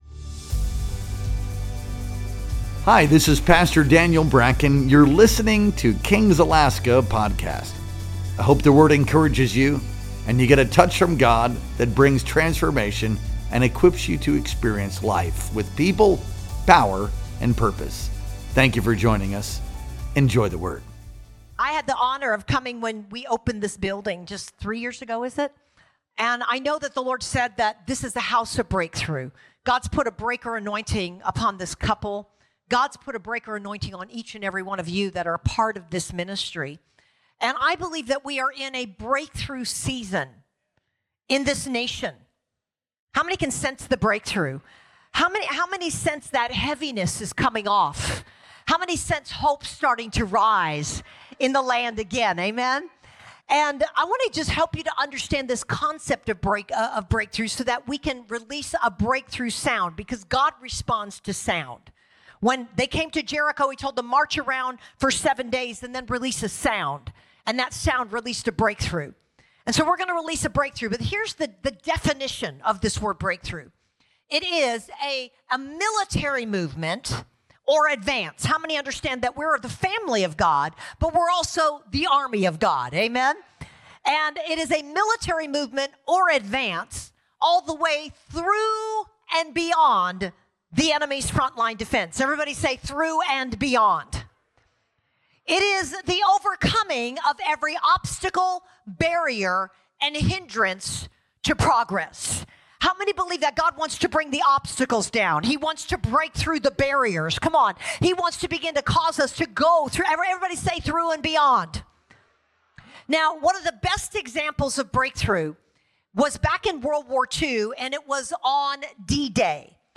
Our Sunday Power Conference Worship Experience streamed live on February 16th, 2025.